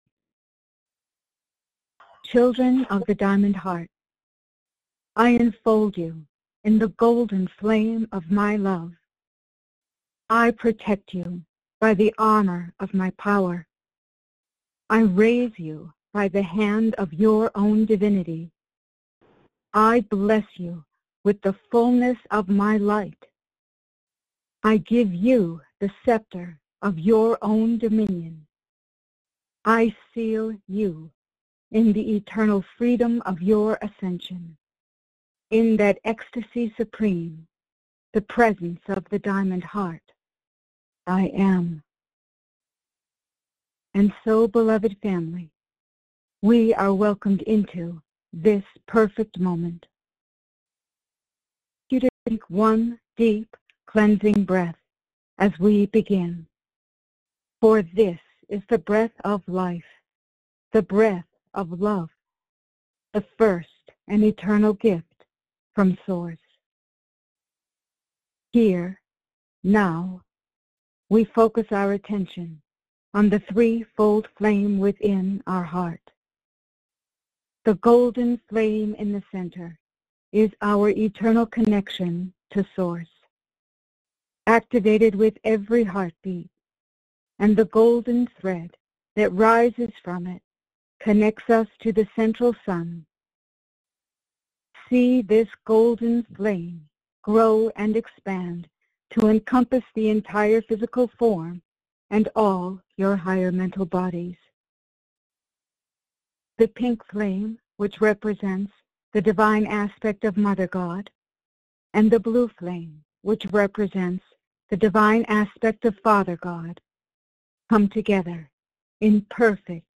Audio Recording Meditation – Minute (00:00)
Channeling – Minute (23:01)